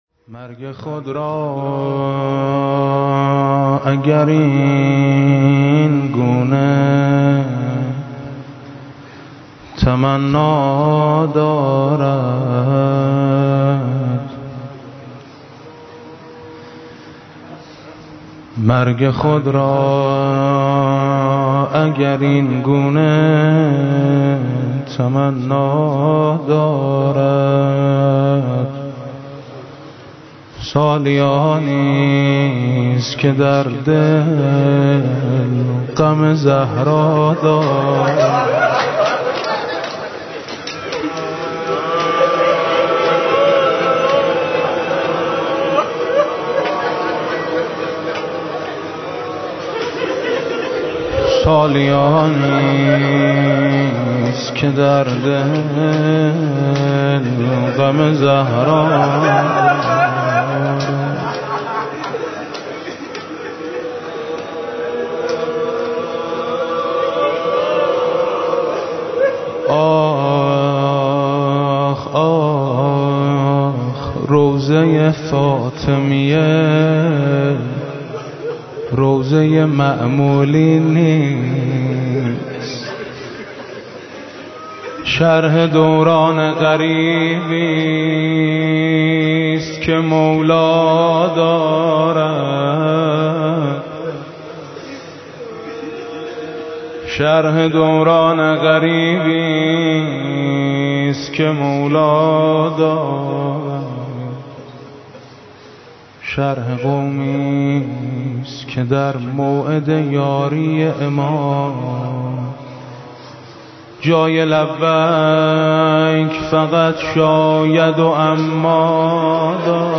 مرثيه و مداحی شهادت امام علی (ع)